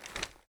gear_rattle_weap_medium_03.ogg